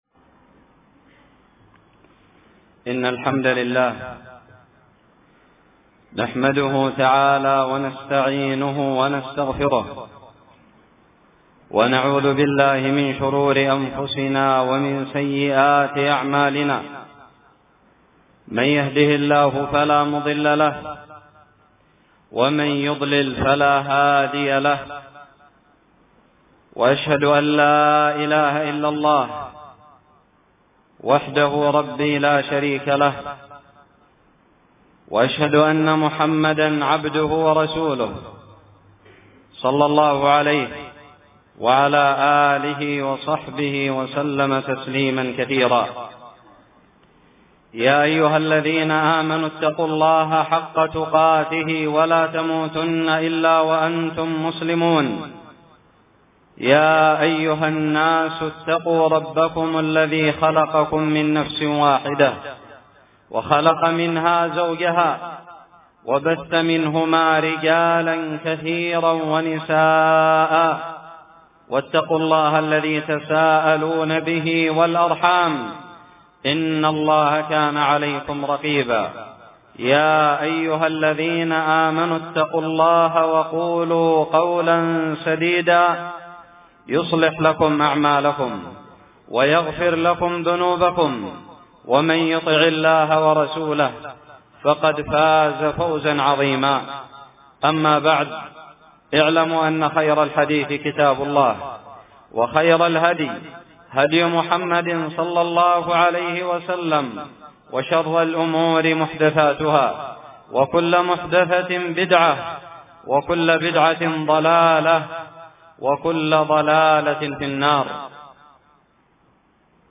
خطب الجمعة
ألقيت بدار الحديث السلفية للعلوم الشرعية بالضالع في عام 1438هــ